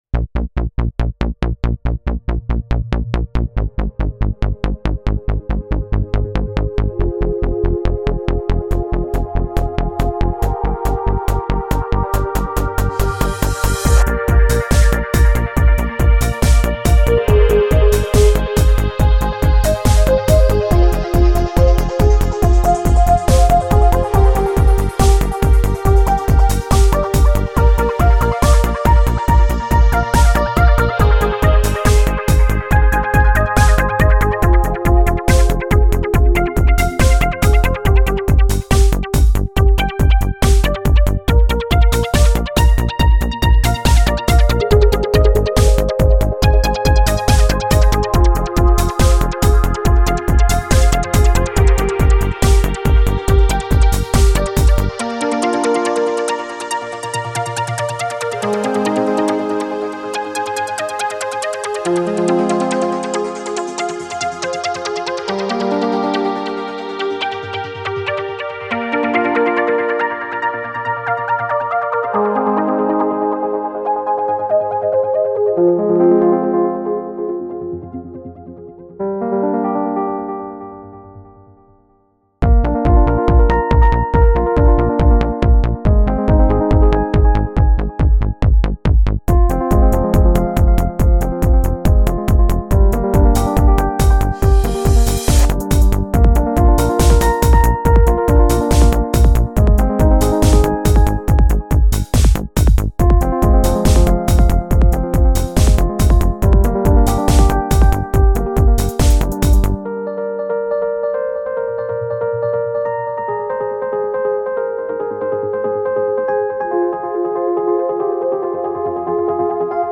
A trance song